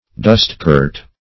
dustcart \dust"cart\ (d[u^]st"k[aum]rt), n.